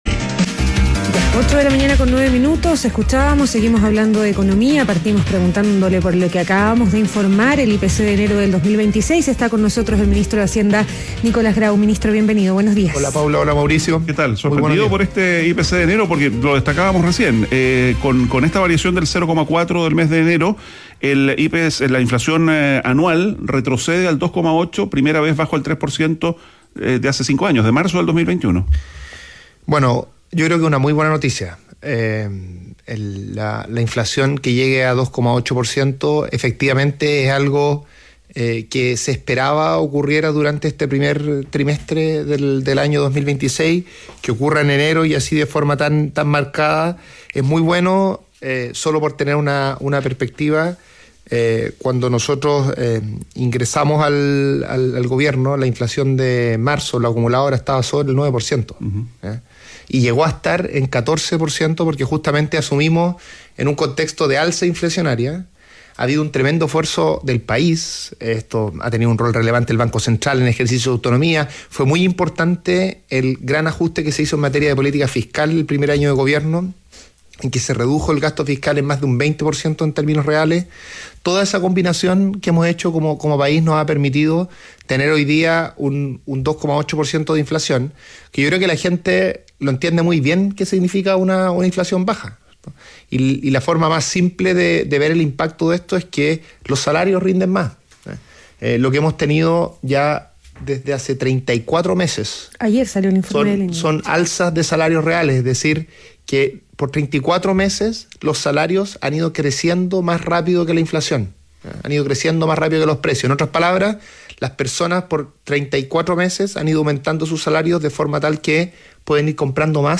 Entrevista a Nicolás Grau, ministro de Hacienda - ADN Hoy